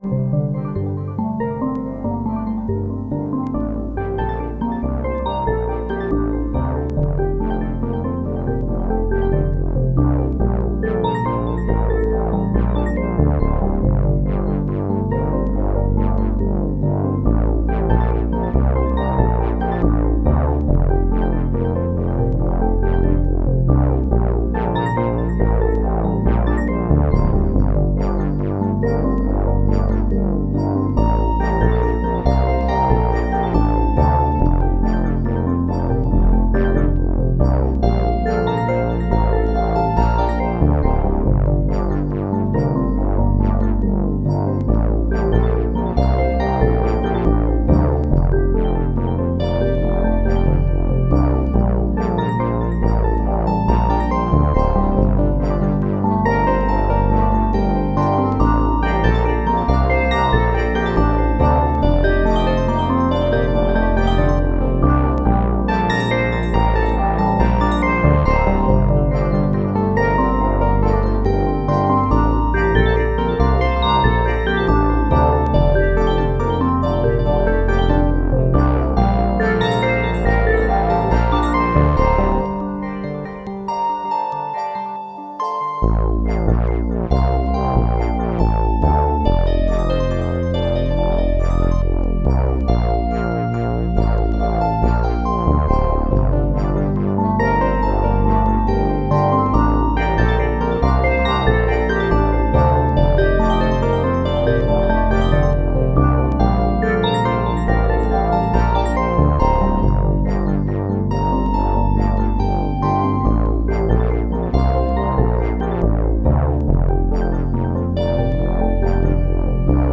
Digitone into OT doing some resampling/delay.